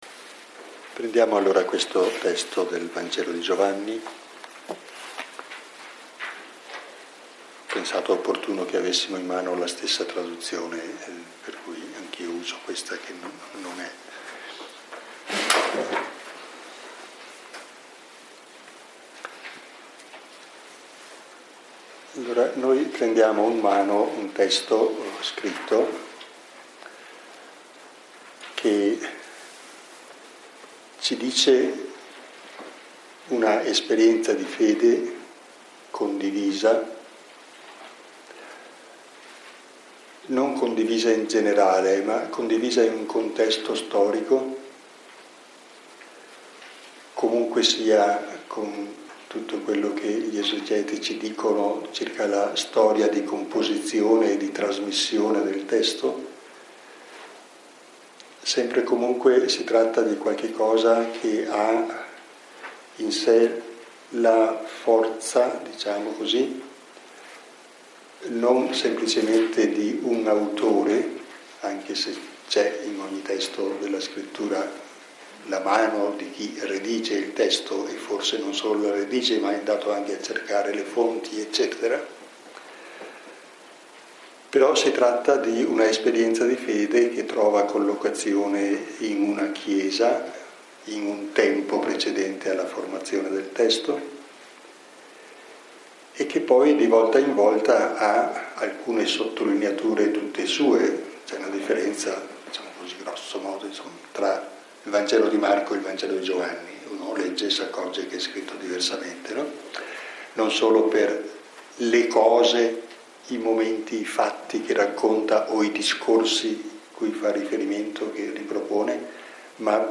Lectio 1 – 20 ottobre 2019 – Antonianum – Padova